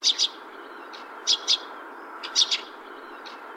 Gorge noire  Chant tchatché : {A2} tchi.tchi
XC349823-L1  Cri : quasi-identique au chant